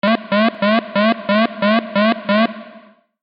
دانلود آهنگ هشدار 14 از افکت صوتی اشیاء
جلوه های صوتی